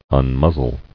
[un·muz·zle]